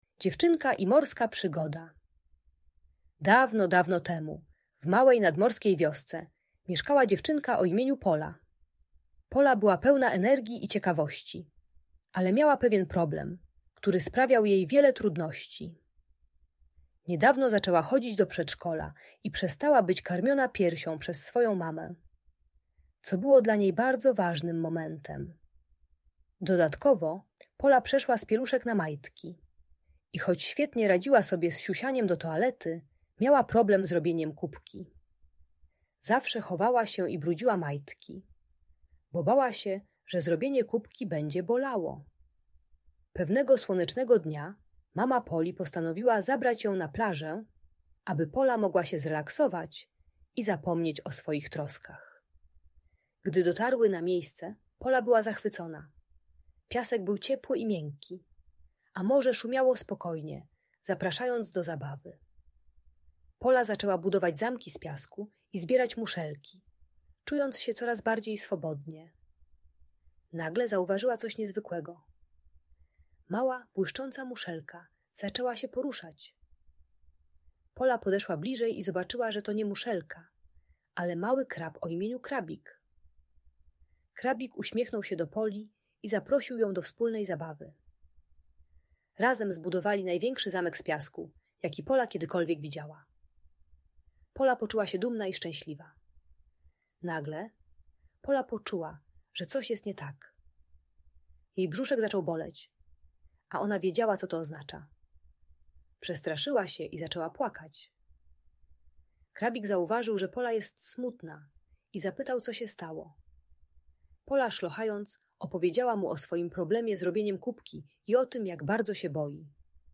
Przygody Poli - Trening czystości | Audiobajka